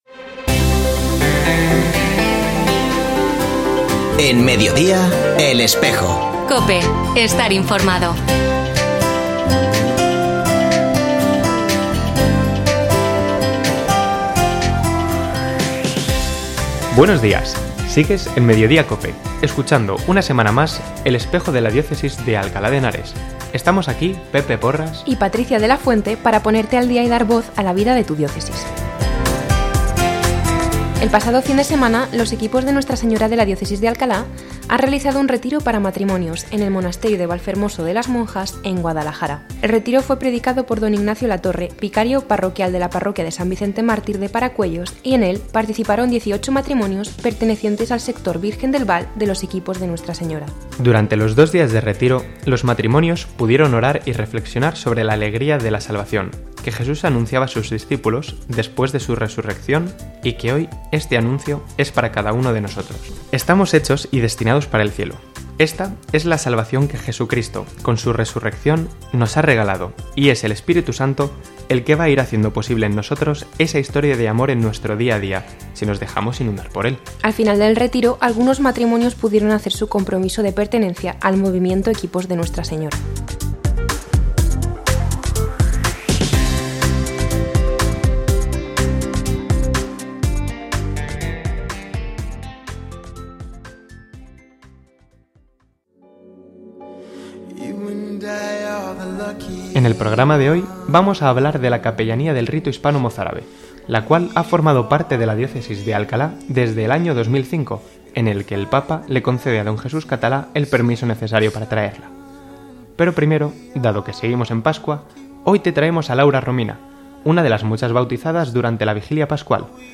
Ofrecemos el audio del programa de El Espejo de la diócesis de Alcalá emitido el viernes 21 de abril de 2023 en radio COPE.